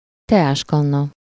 Ääntäminen
Synonyymit bouillotte coquemar Ääntäminen France (Île-de-France): IPA: /buj.waʁ/ Paris: IPA: [buj.waʁ] Tuntematon aksentti: IPA: /bu.ʎwaʁ/ Haettu sana löytyi näillä lähdekielillä: ranska Käännös Ääninäyte 1. kazán 2. teáskanna Suku: f .